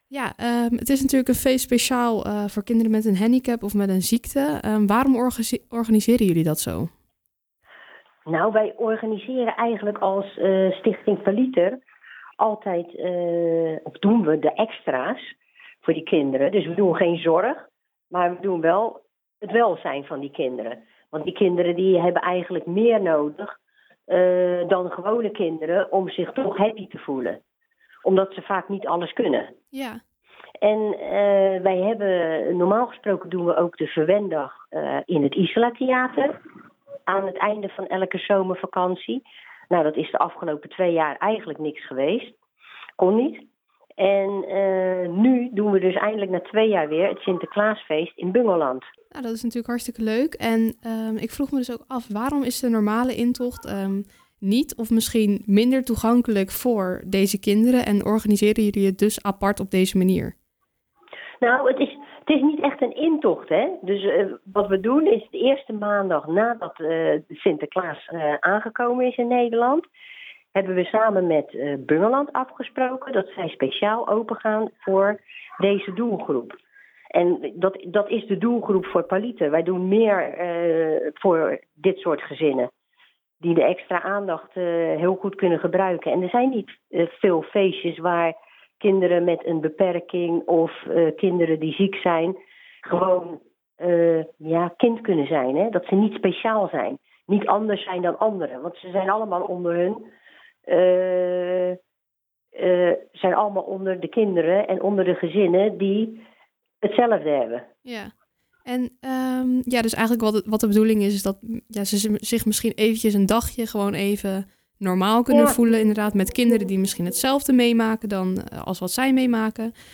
gaat erover in gesprek met